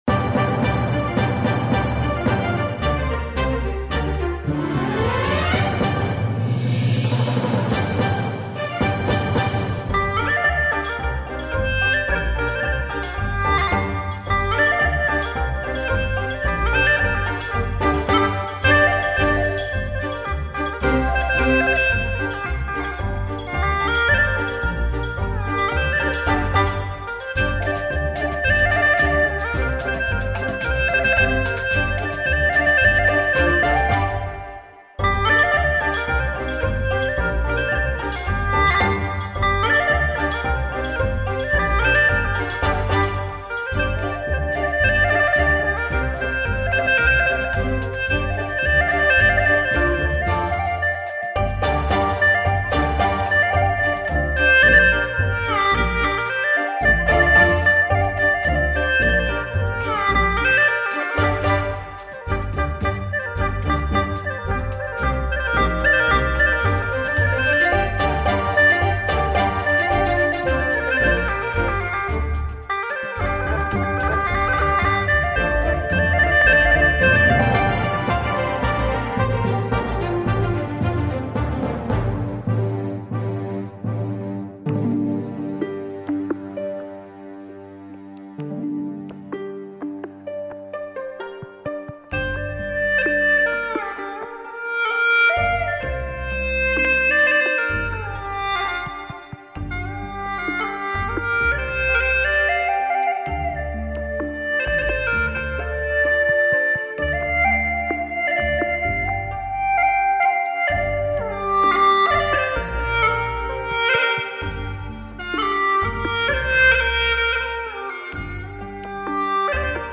调式 : 降B 曲类 : 独奏
藏族弦子风格，旋律明快流畅，充满弦子舞特有的韵律。